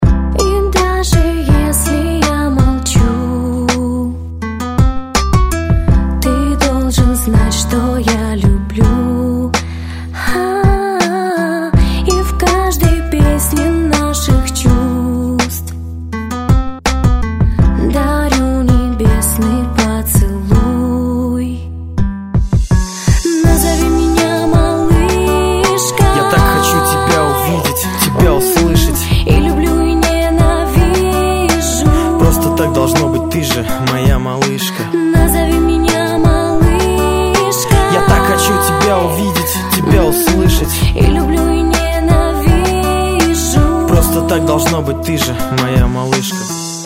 Категория : Попса (реалтоны)